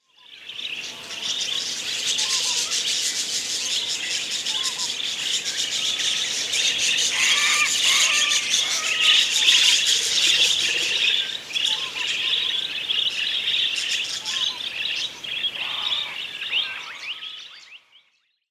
Common Behaviors and Vocalizations
From their earliest days, they communicate using soft, high-pitched chirps that serve as contact calls to parents and siblings.
This is the stage where you might hear varied warbles, trills, or even attempts to mimic environmental sounds.